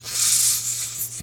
snake_2_hiss_05.wav